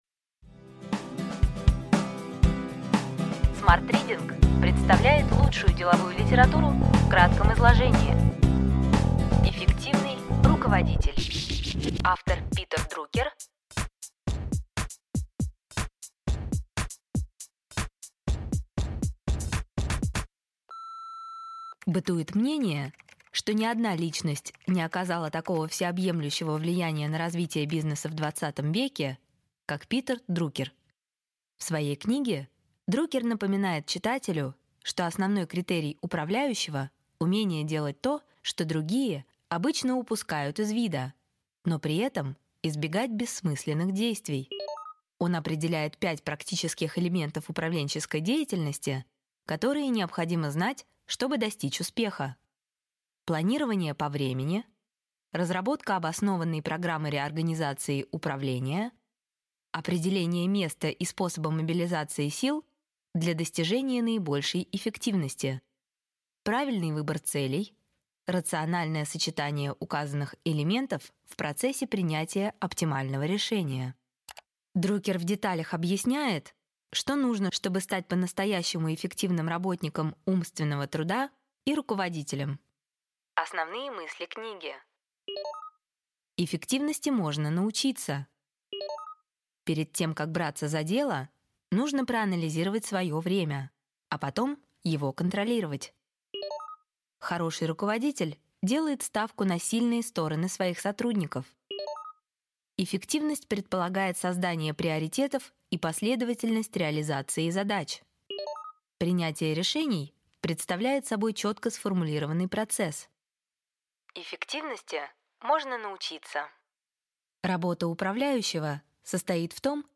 Аудиокнига Эффективный руководитель
Качество озвучивания весьма высокое.